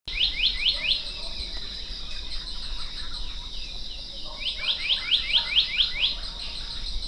54-1柴山2011黑枕may30-1.mp3
黑枕藍鶲(台灣亞種) Hypothymis azurea oberholseri
高雄市 鼓山區 柴山
錄音環境 次生林
Denon Portable IC Recorder 型號 DN-F20R 收音: 廠牌 Sennheiser 型號 ME 67